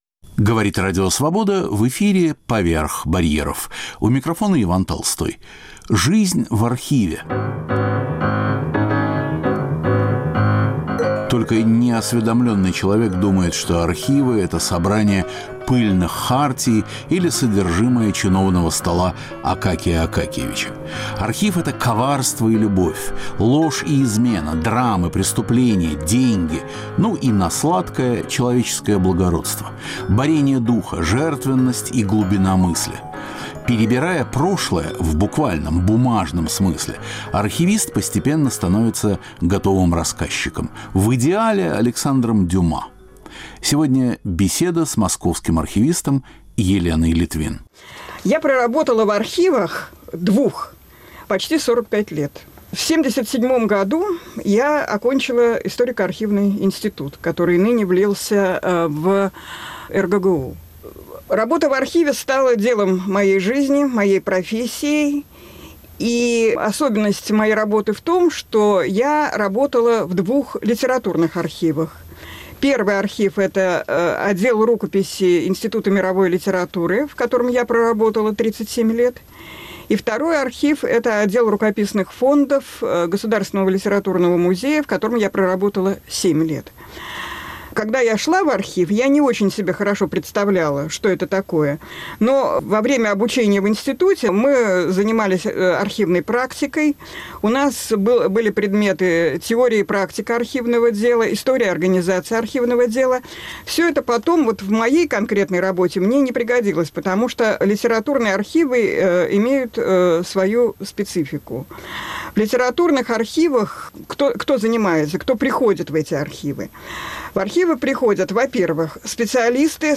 Каждый выпуск программы сопровождают новые музыкальные записи.